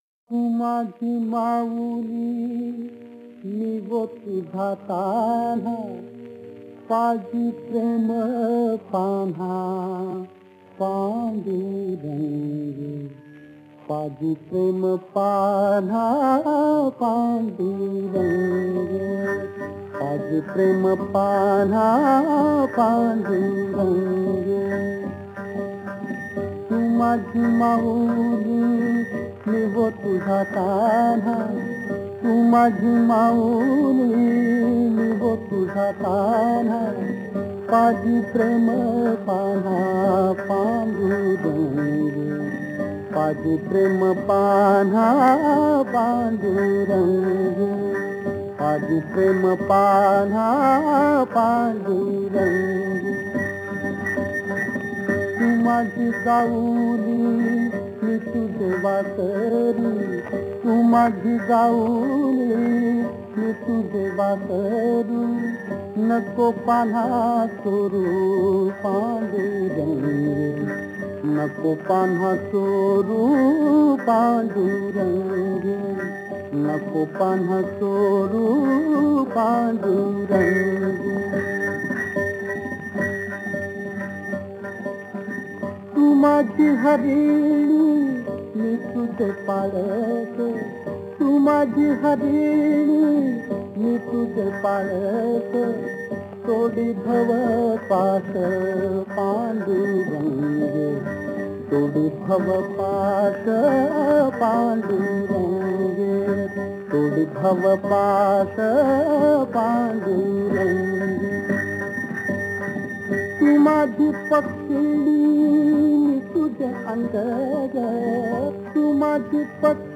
अभंग